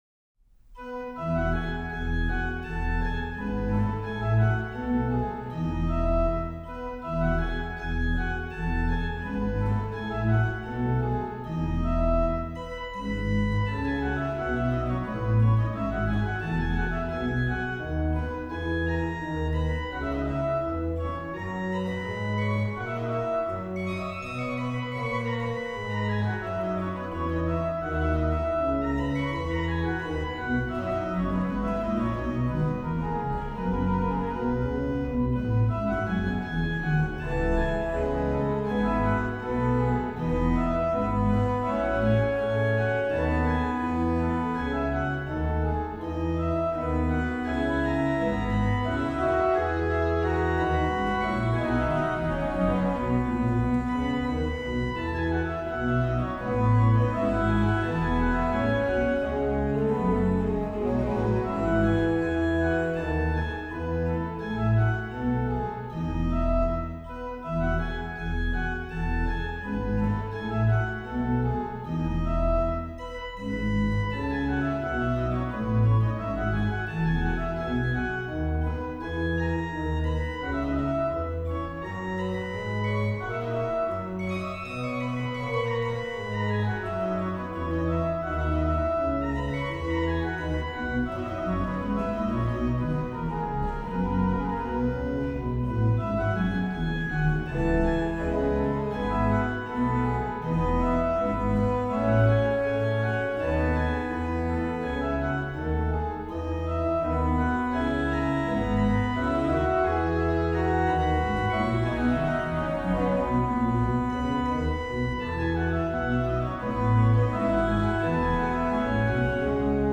Subtitle   à 2 Clav. et Pedal; anto fermo in Tenore
Venue   1724-30 Trost organ, Stadtkirche, Waltershausen, Germany
Registration   rh: BW: Ged8, Pr4
lh: HW: Pr8, Viol8, Pr4, Tr8
Ped: Sub16, Oct8, Viol8